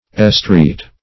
Estreat - definition of Estreat - synonyms, pronunciation, spelling from Free Dictionary
Estreat \Es*treat"\, v. t. [imp. & p. p. Estreated; p. pr. &
estreat.mp3